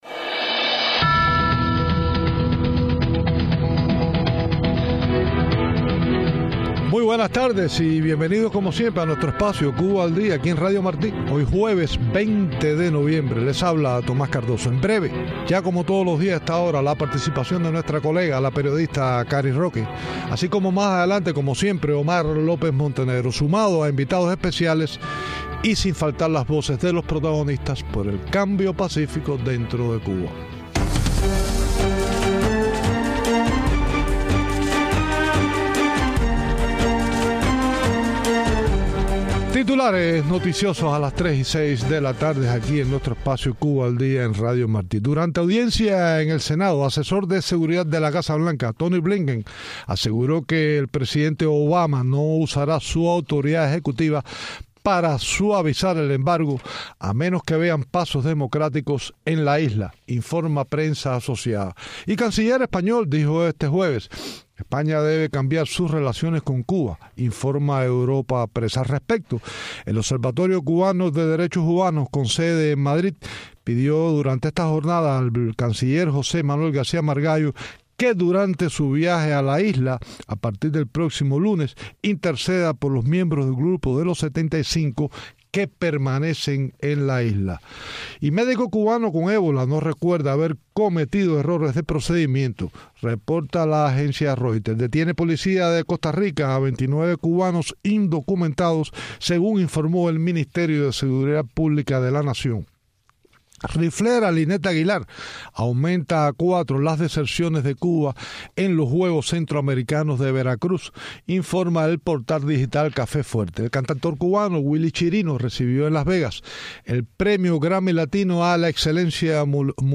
Entrevista
Comenzamos con titulares noticiosos